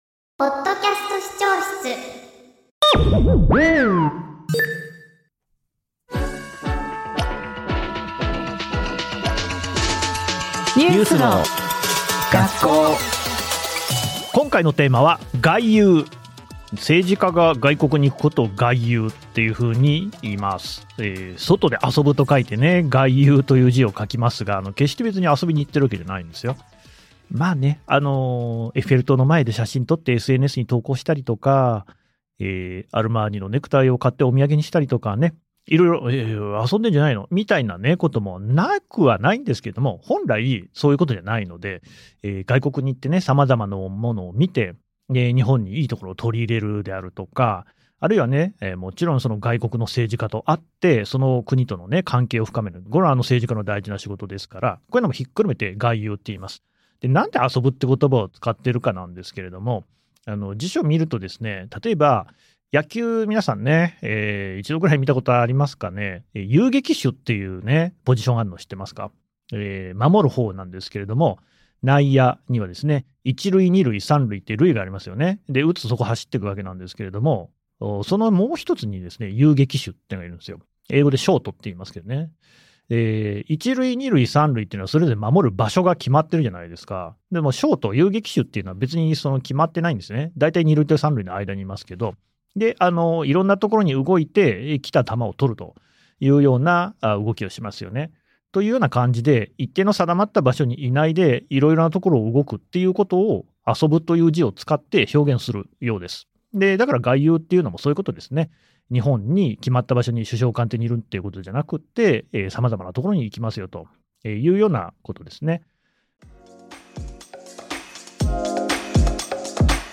気になるニュースのキーワードを、サクっと10分ほどで学べる番組です。おしゃべり感覚だから聞きやすい！